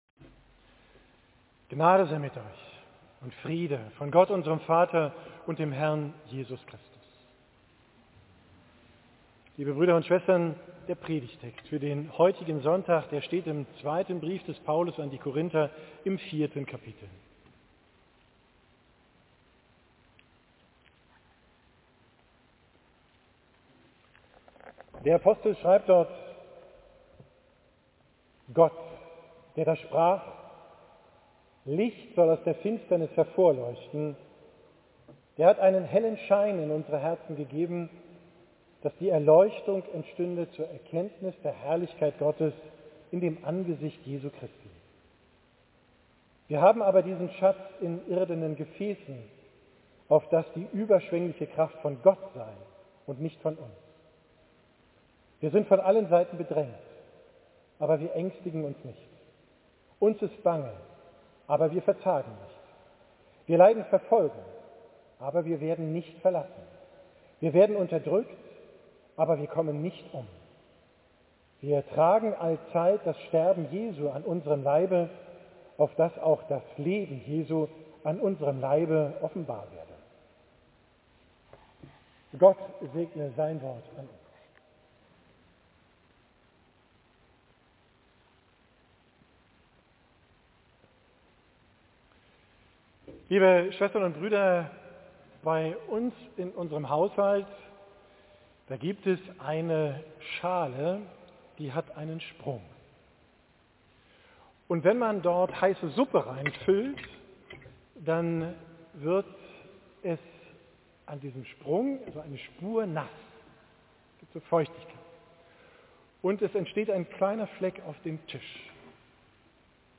Predigt vom letzen Sonntag nach Epiphanias, 28.